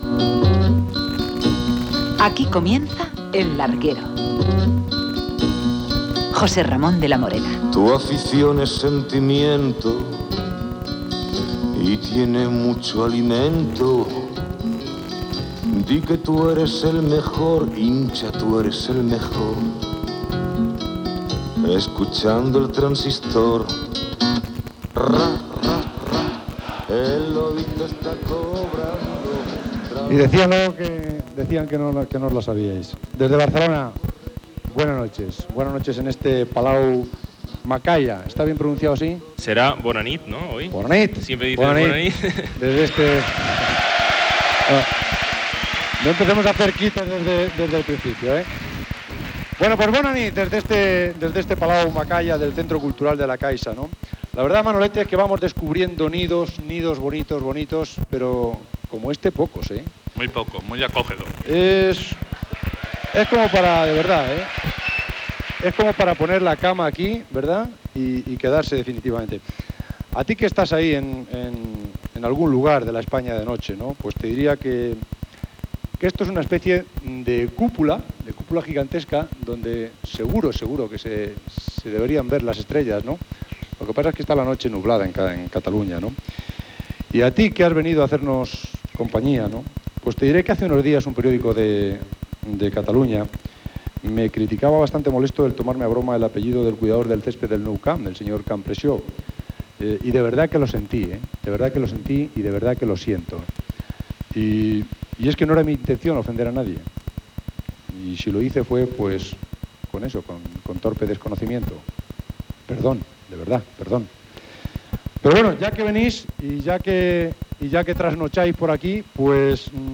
Inici del programa amb la presentació de l'equip. "Carrusel Deportivo" humorístic.
Esportiu
Programa fet des de Barcelona, al Palau Macaya del Centre Cultural la Caixa.